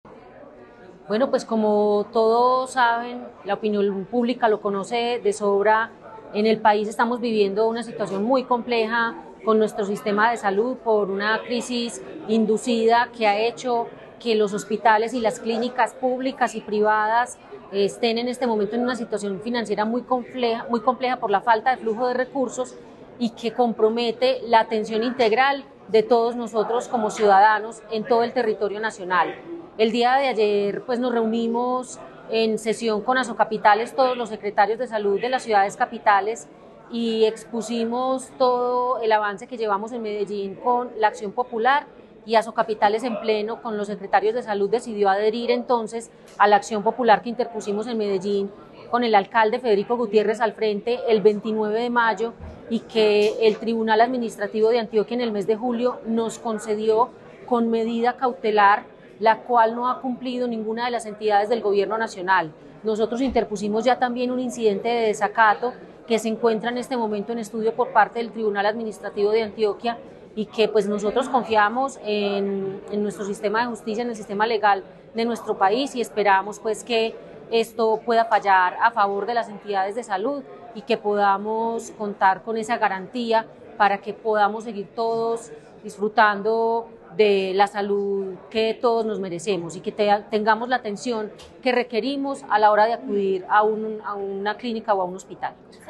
Declaraciones de la secretaría de Salud, Natalia López Delgado
Declaraciones-de-la-secretaria-de-Salud-Natalia-Lopez-Delgado-1.mp3